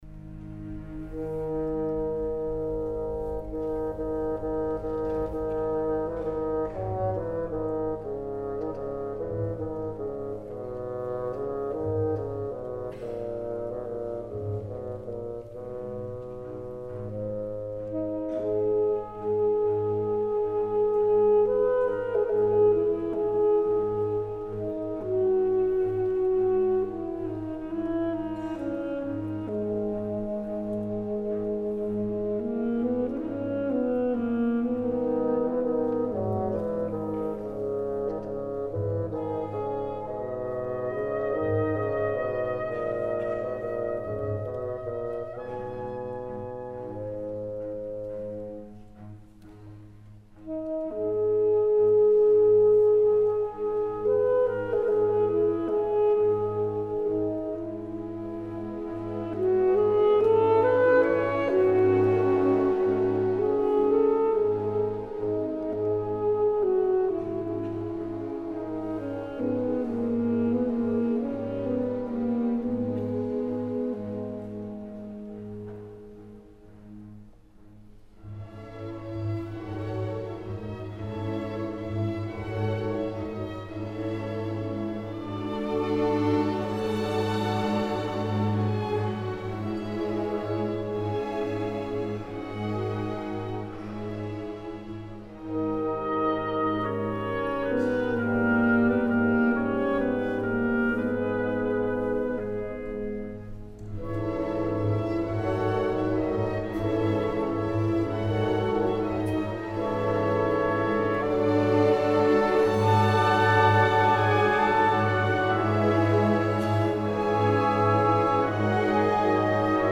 "Vecchio Castello" da Quadri di una esposizione di M.Musorgskij - Orchestra Sinfonica della RAI
Direttore: D.Kitaenko